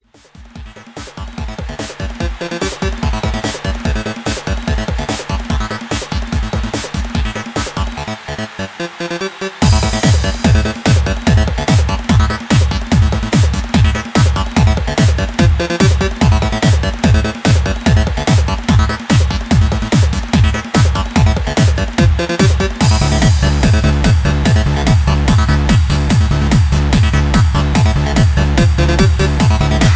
remix handsup